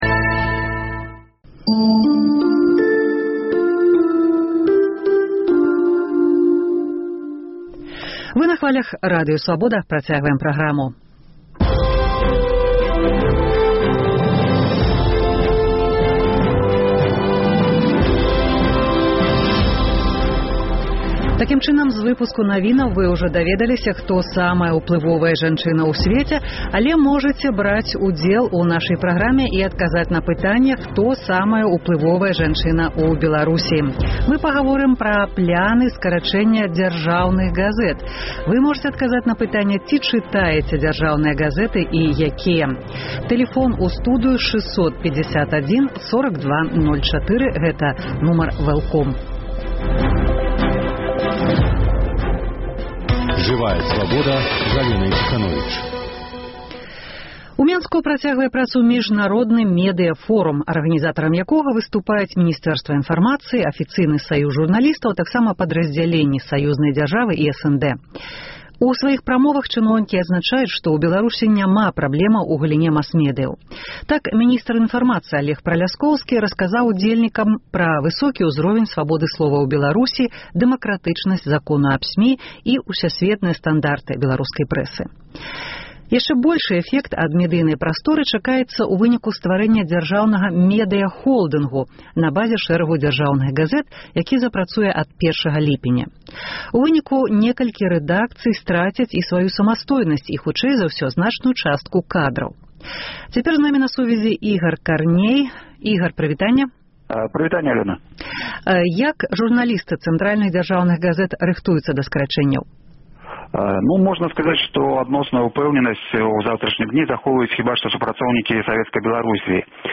Госьць эфіру – дыплямат, перакладчык, апякун культурных праектаў